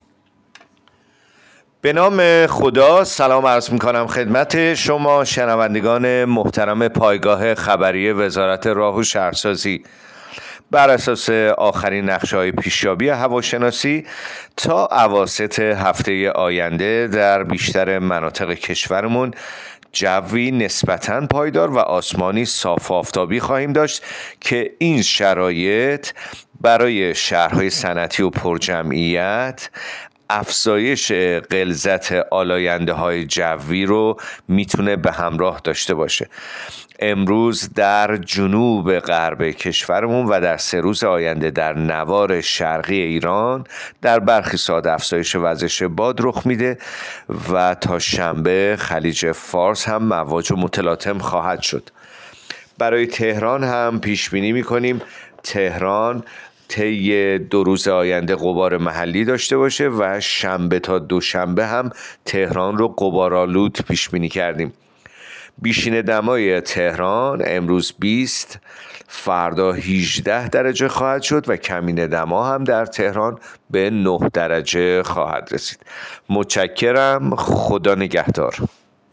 گزارش رادیو اینترنتی پایگاه‌ خبری از آخرین وضعیت آب‌وهوای ۲۹ آبان؛